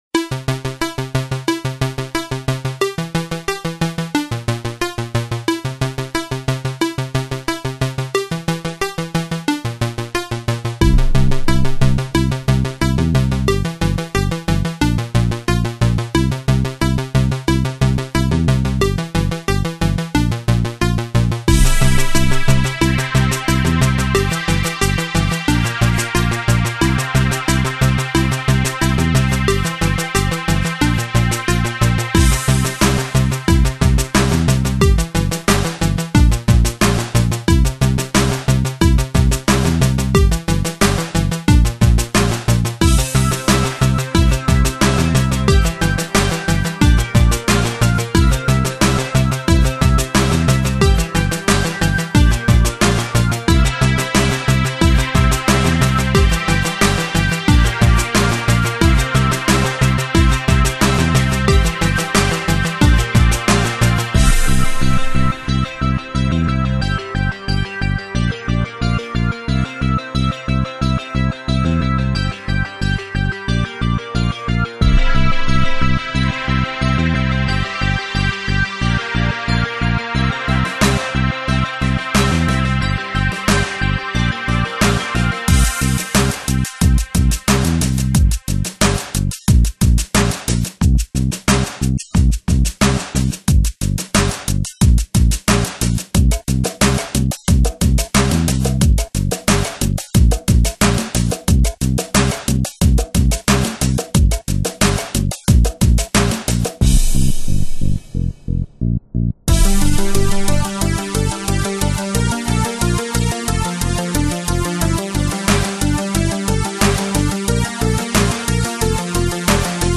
Subject description: Trance alert!
A happy one, not really Trance anymore.